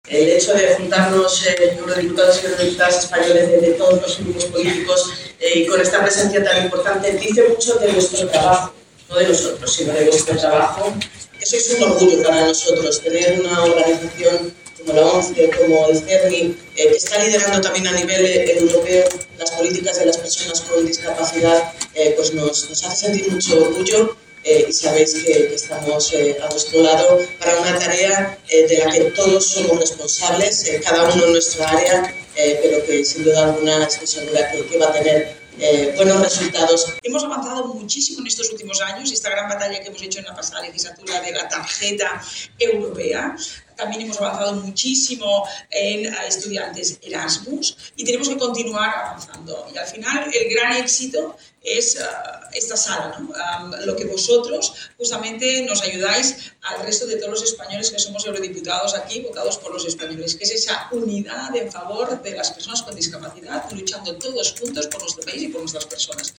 En el encuentro, organizado por la Oficina Técnica de Asuntos Europeos del Grupo Social ONCE, los nuevos eurodiputados españoles elegidos en los últimos comicios europeos conocieron de primera mano el modelo de la Organización y mostraron sus propuestas sobre estas temáticas: intervinieron Dolors Montserrat y Rosa Estarás (PPE); Iratxe García (S&D); Jorge Buxadé (PfE); Diego Solier (ECR); Oihane Agirregoitia (Renew Europe); y Estrella Galán (La Izquierda).